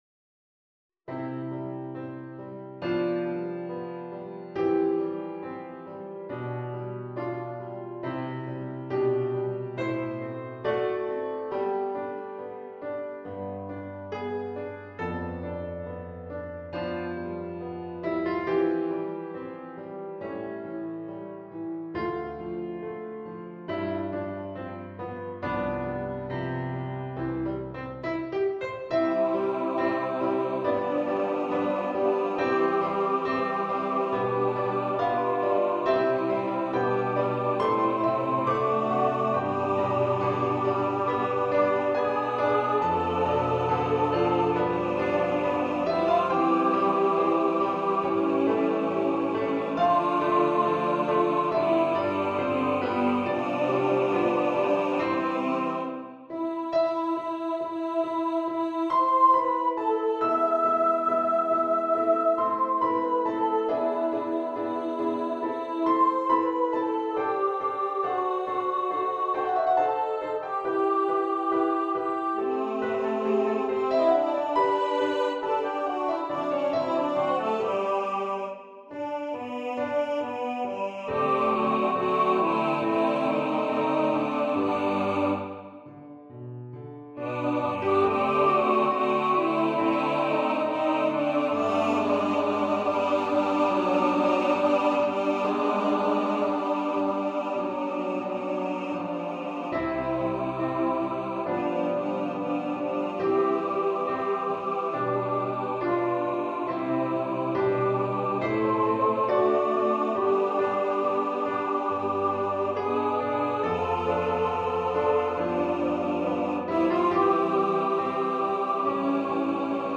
for SA+Men
A carol for Christmas
for choir with orchestra or piano
Timpani, Percussion (1 player: Cymbals)
Strings (Violin 1, Violin 2, Viola, Cello, Bass)
Choir (SATB or SA+Men or SSA or TTBB)